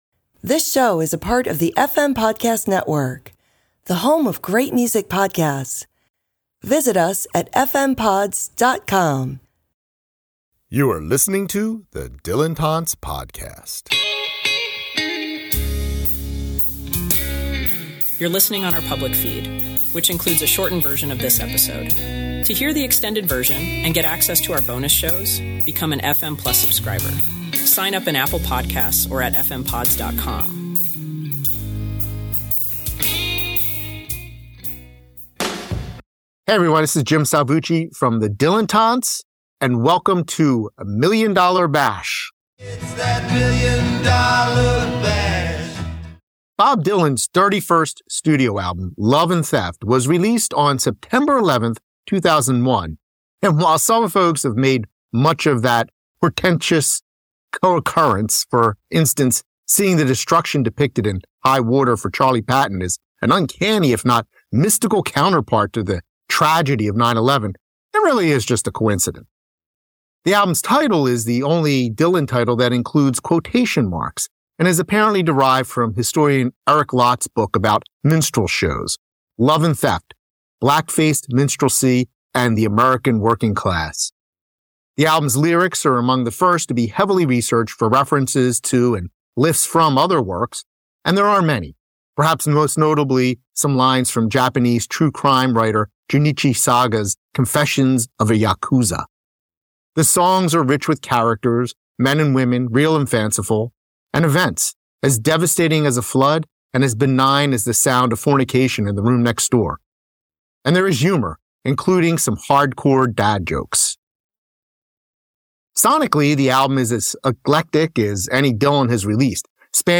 A Million $ Bash Roundtable Bob Dylan’s 31st studio album, “Love and Theft,” was released on September 11, 2001.